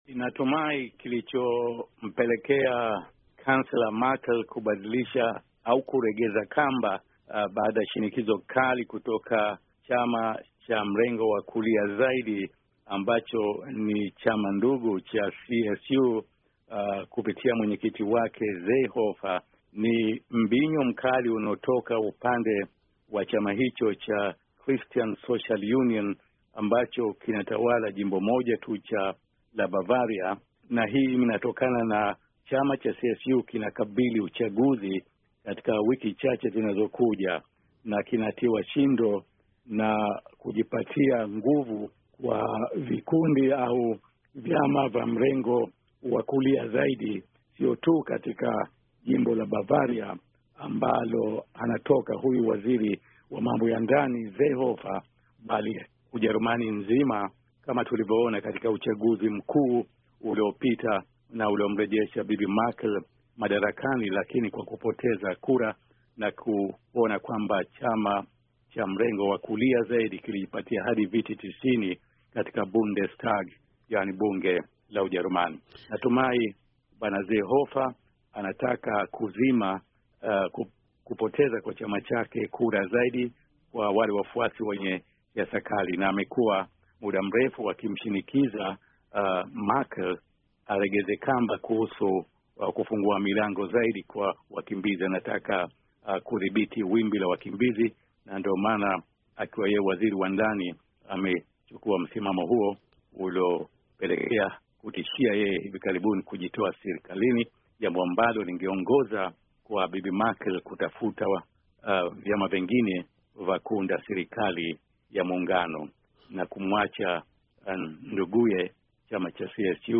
VOA imezungumza na mchambuzi wa masuala ya siasa na mwandishi wa habari mstaafu